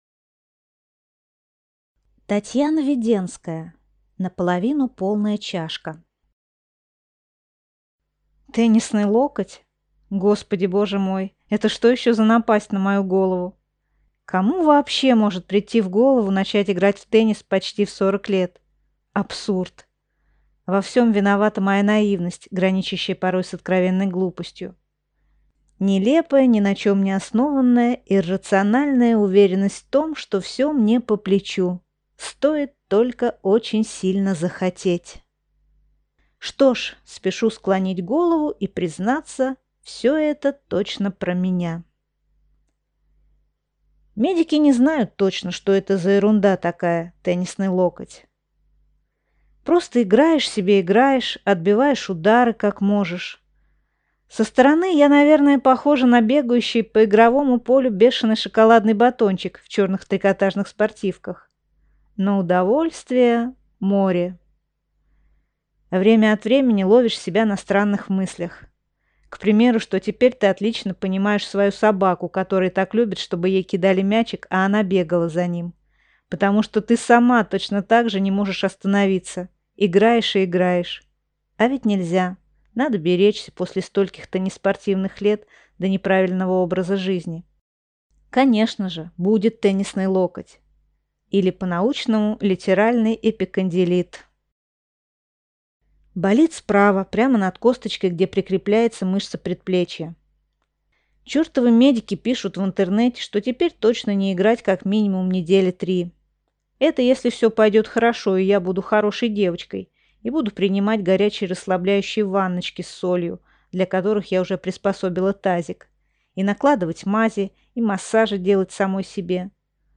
Аудиокнига Наполовину полная чашка | Библиотека аудиокниг